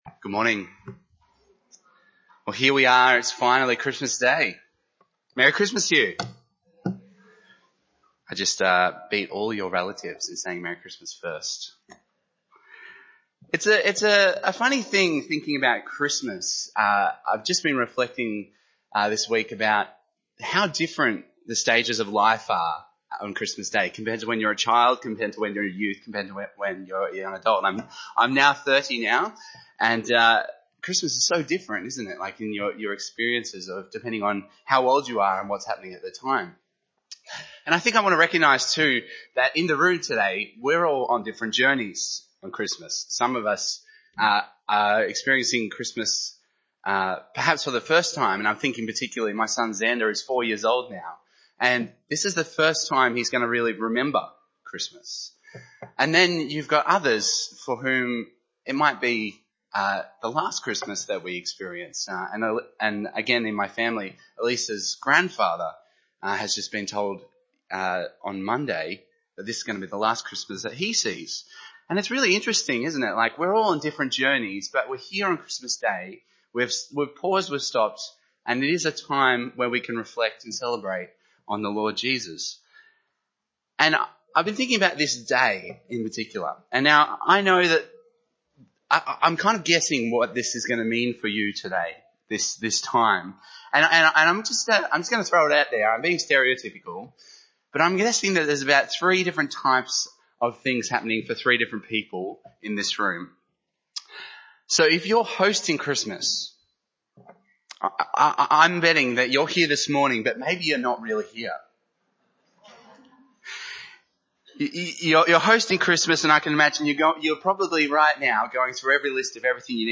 Service Type: Special Events